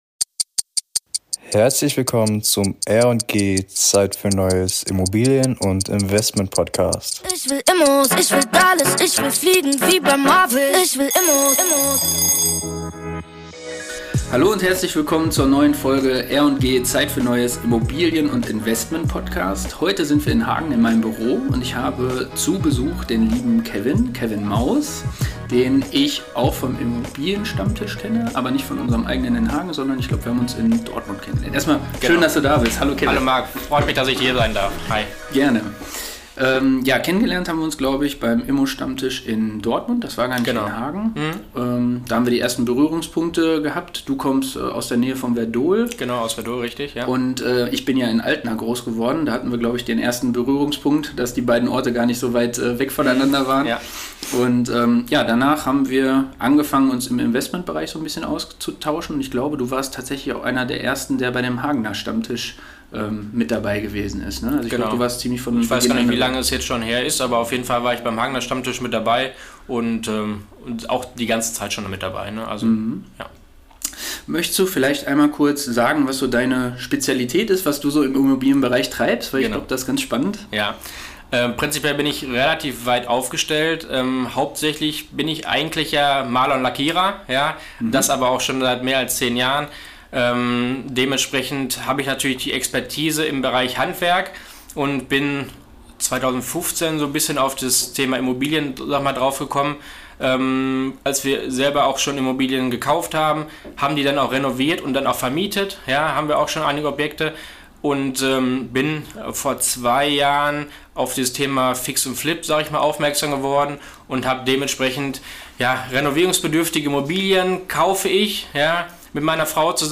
Herzlich Willkommen beim R&G Zeit für Neues Immobilien- und Investmentpodcast! Die verschiedenen Interview Gäste aus den Bereichen der Immobilienwirtschaft geben euch ebenfalls Einblicke in die wichtigsten Themen.